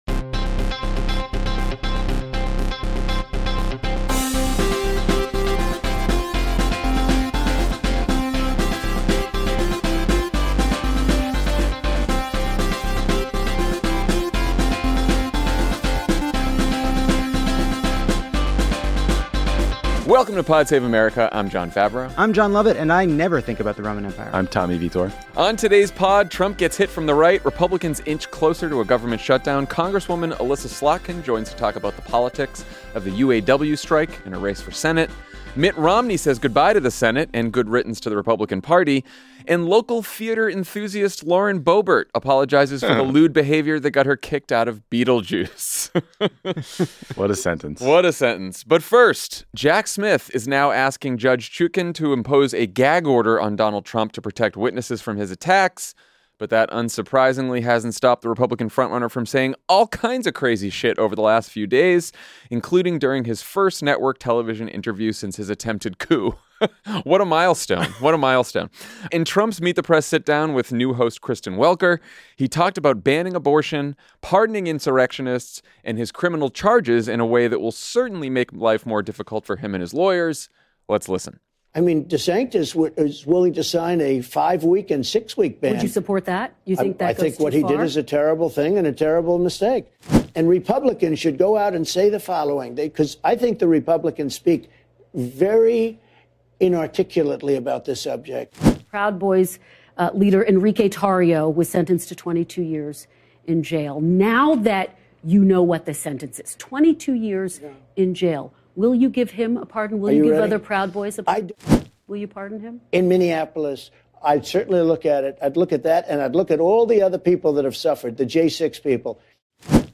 Then, Michigan Congresswoman Elissa Slotkin joins the show to discuss the United Auto Workers' strike and her campaign for Senate.